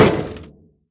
metal_place_0.ogg